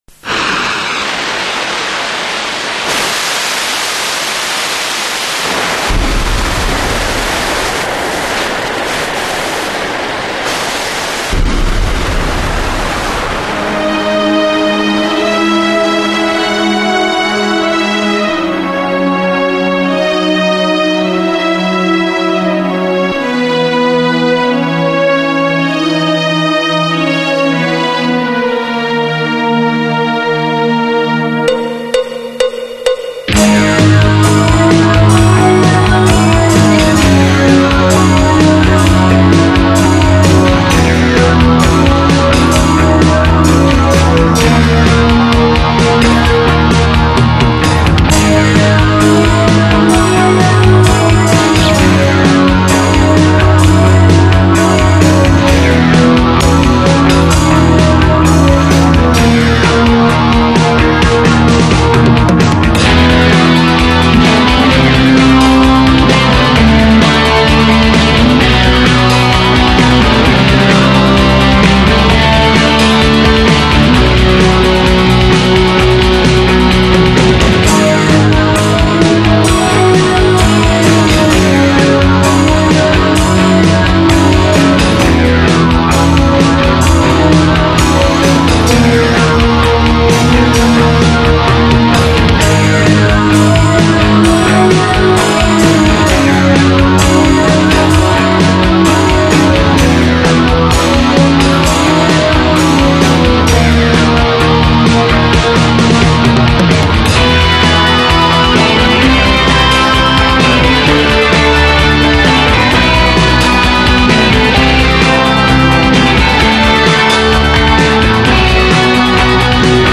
voix, claviers, guitares,basse et programmation batterie
La Fiction pop-rock